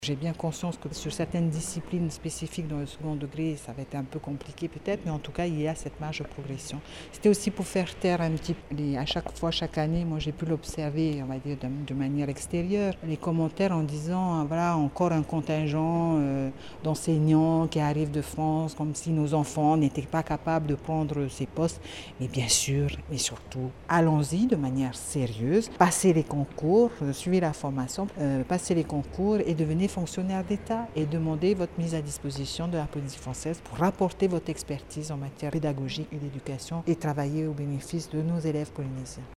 La nouvelle ministre de l’Education Téa Frogier et le vice-recteur Philippe Couturaud ont tenu une conférence de presse de pré-rentrée vendredi.